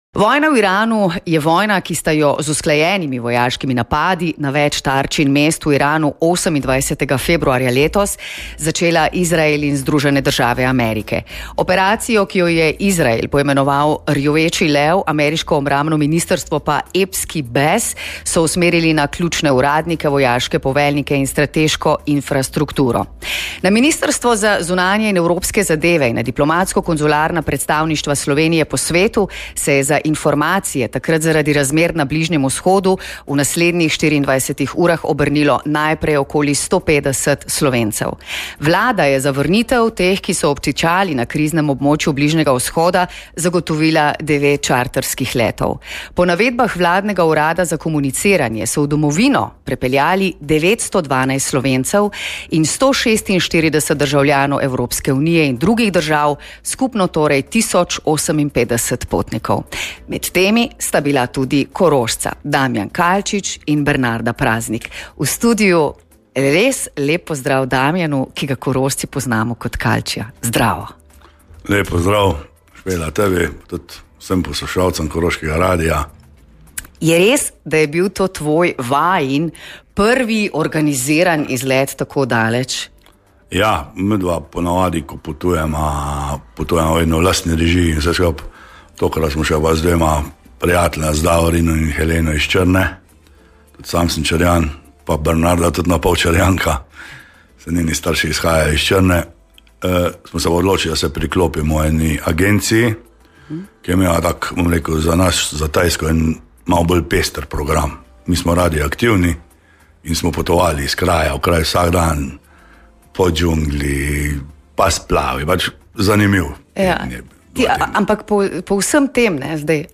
Dneve negotovosti sta preživela v hotelu v Dohi, brez prtljage in z vprašanjem, kdaj se bosta lahko vrnila domov. V pogovoru